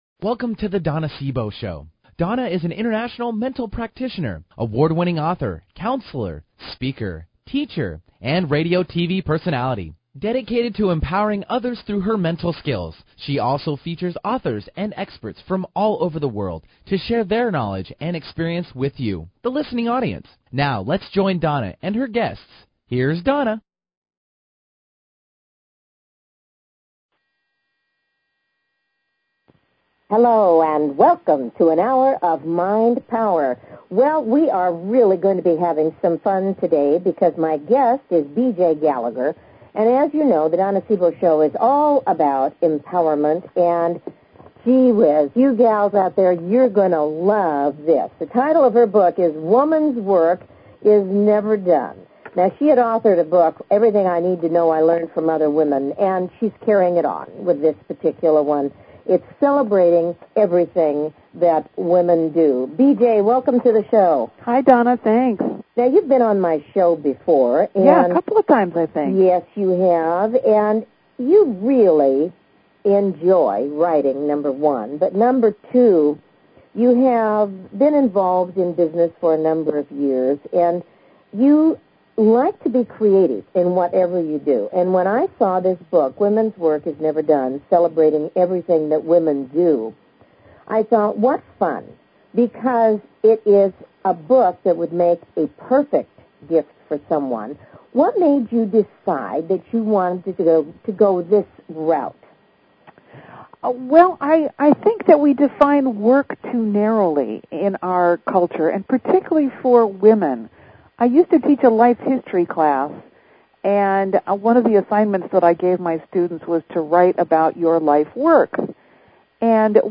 Talk Show Episode
Fun and full of facts join us for a light and happy conversation of appreciation of the women in our world.